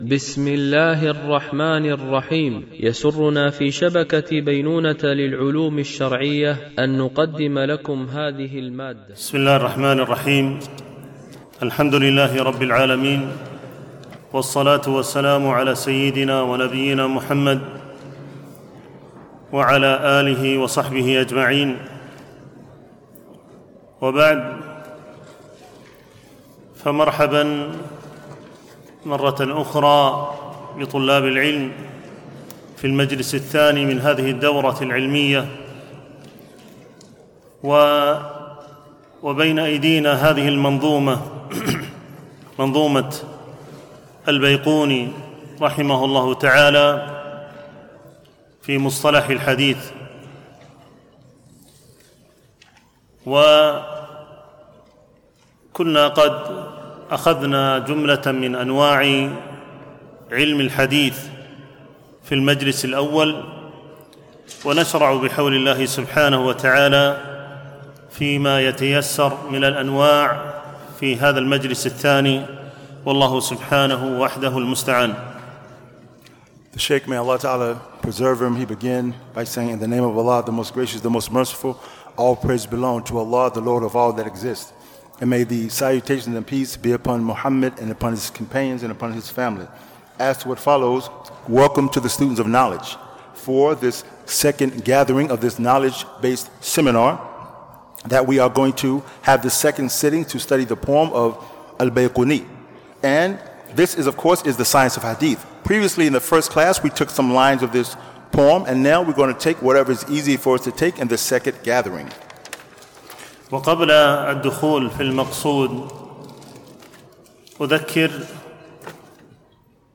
بمسجد أم المؤمنين عائشة رضي الله عنها